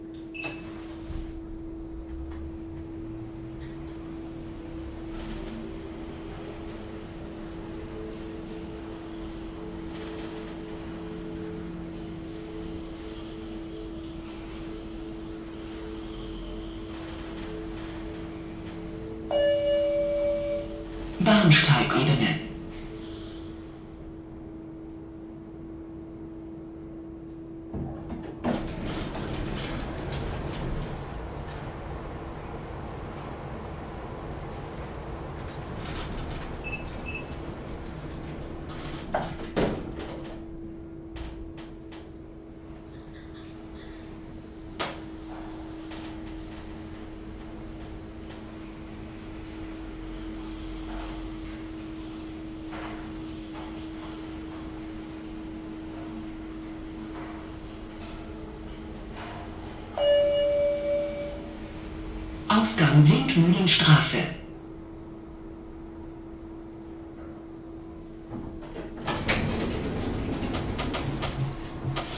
电梯
描述：乘坐电梯。 PCMD100的麦克风内部。
标签： 电梯 现场记录 电梯
声道立体声